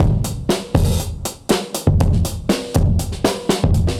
Index of /musicradar/dusty-funk-samples/Beats/120bpm/Alt Sound
DF_BeatA[dustier]_120-01.wav